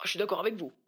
VO_ALL_Interjection_19.ogg